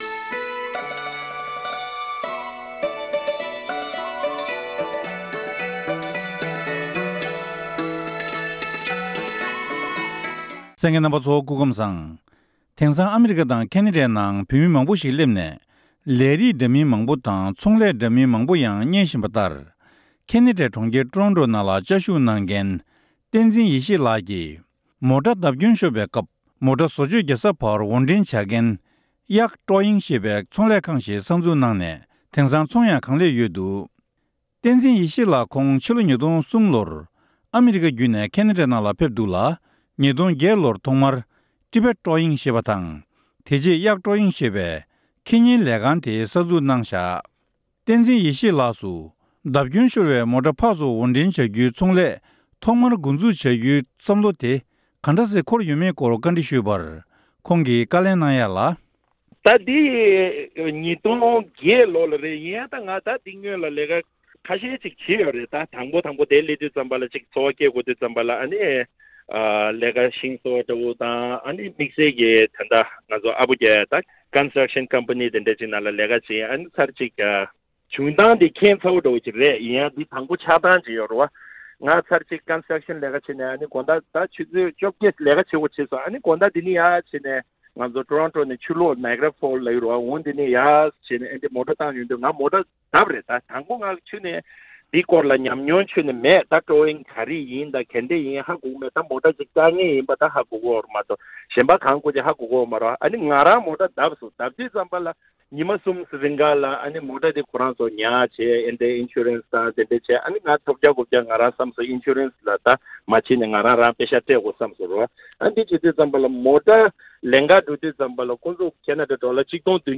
བཅར་འདྲི་ཞུས་ནས་ཕྱོགས་སྒྲིག་ཞུས་པ་ཞིག་གསན་རོགས་གནང་།།